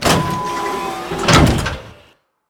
ElevatorOpen3.ogg